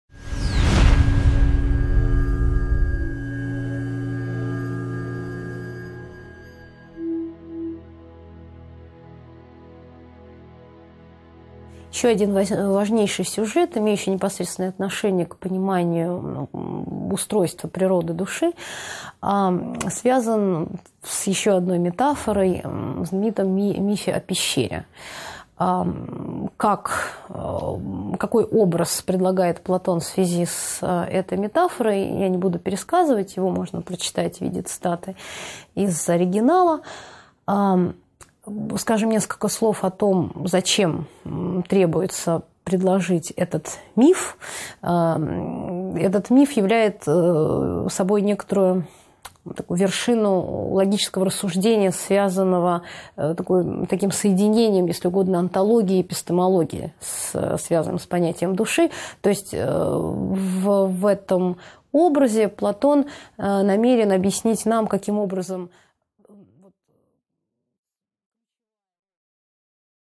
Аудиокнига 3.4 Платон: Миф о пещере | Библиотека аудиокниг